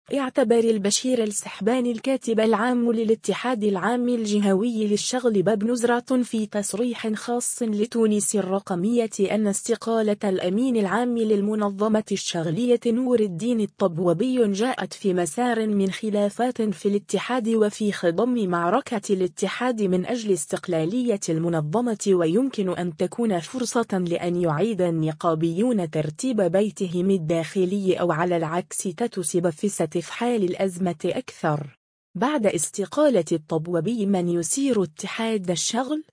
تصريح خاص